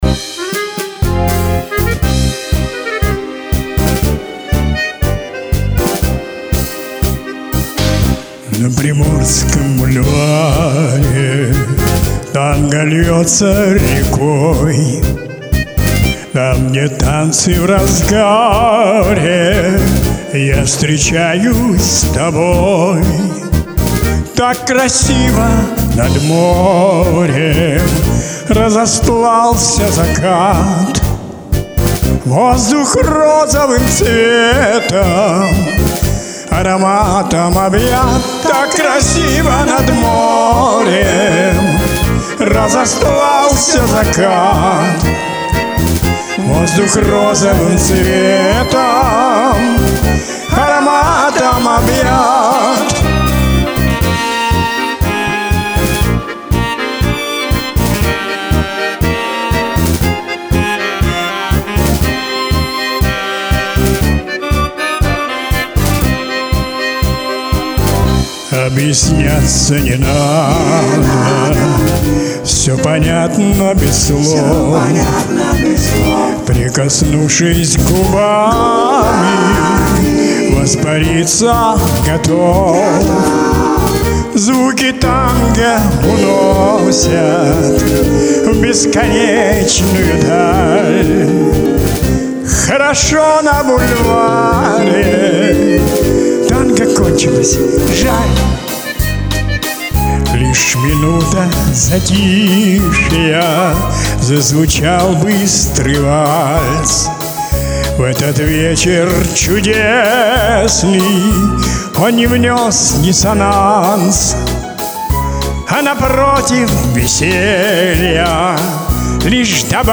Номинация «ПЕСНЯ»